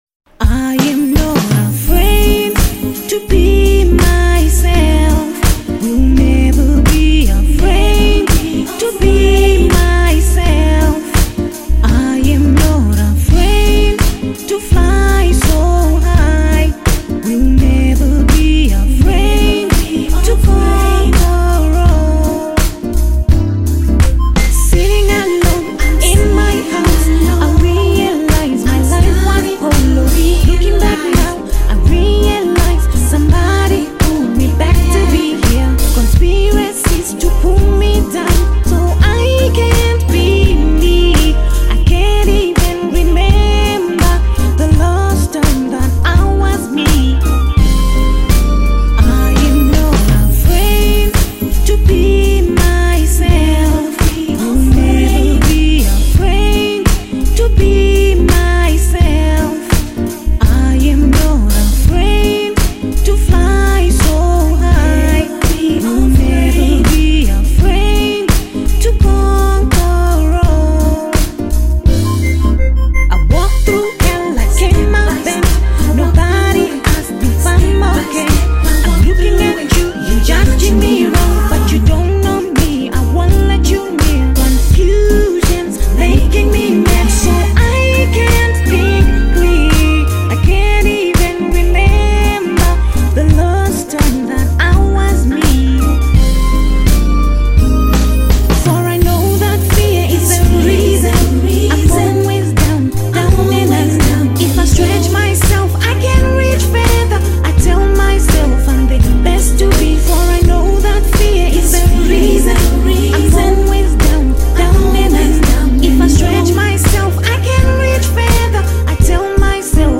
GOSPEL SONG WRITER/SINGER